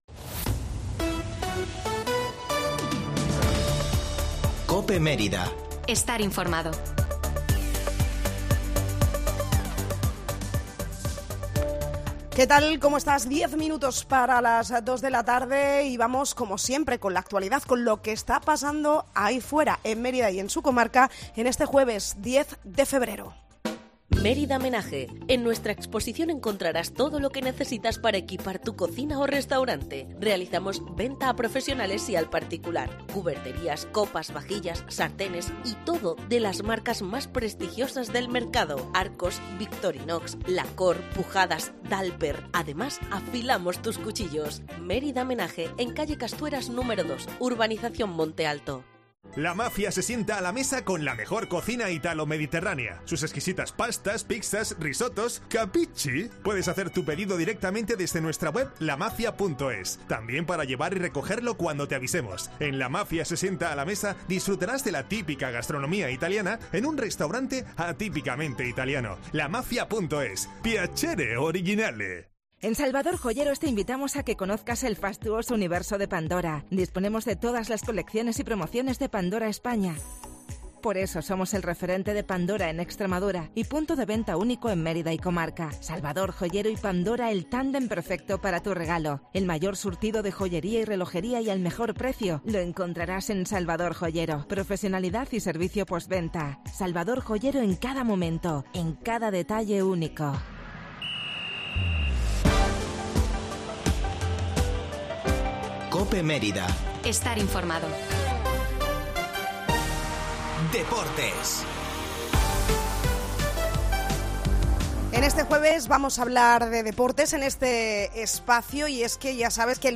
La tertulia